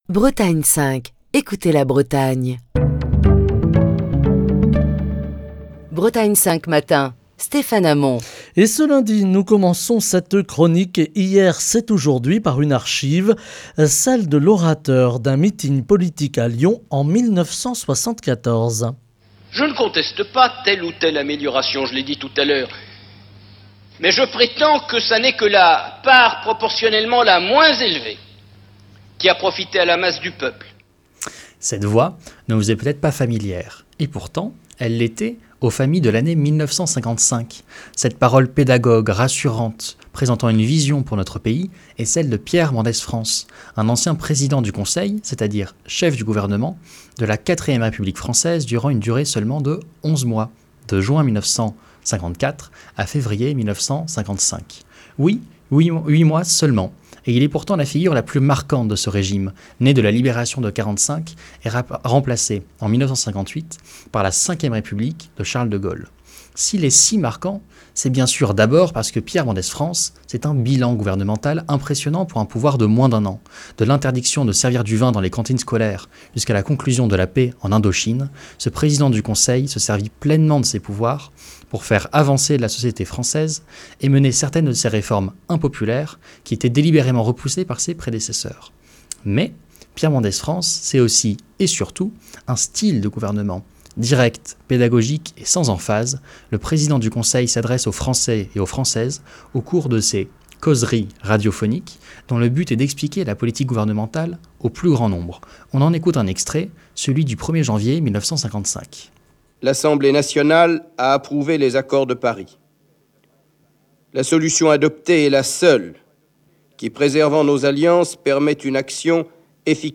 Chronique du 31 octobre 2022.